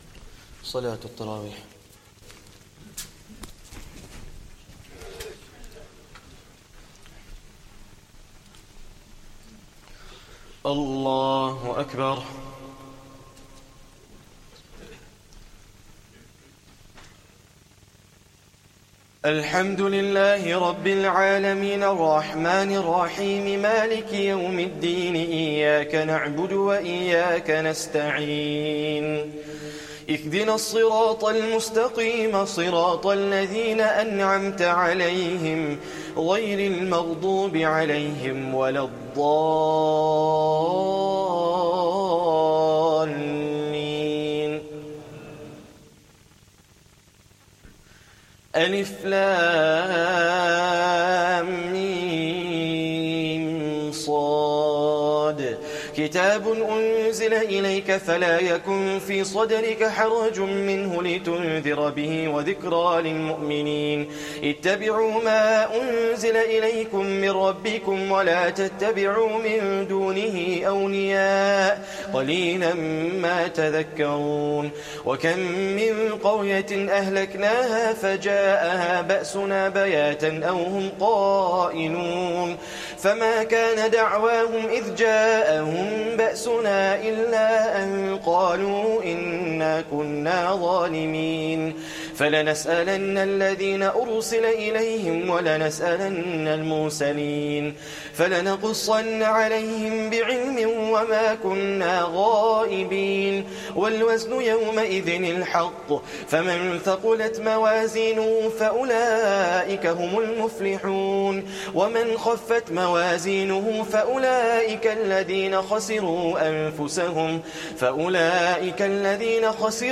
Taraweeh Prayer 7th Ramadhan